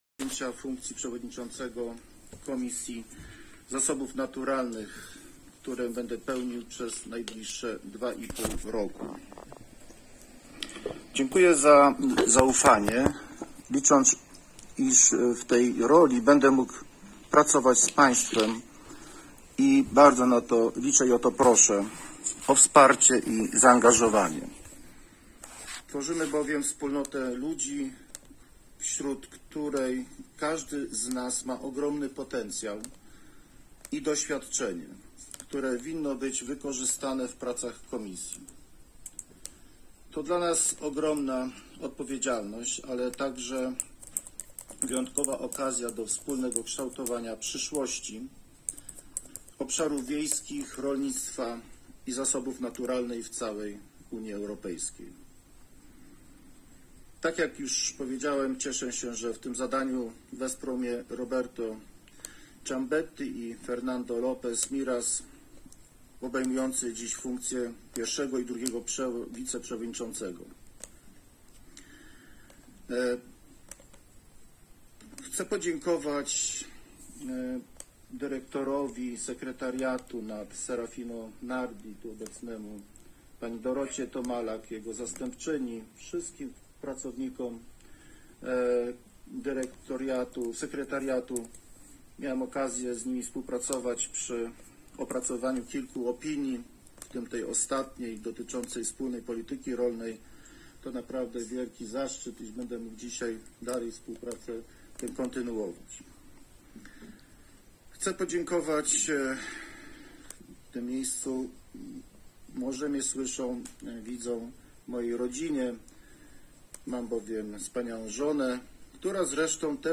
– powiedział marszałek Piotr Całbecki podczas wystąpienia po wyborze.
marszalek-po-wyborze-na-przewodniczacego-komisji-NAT-w-Brukseli.m4a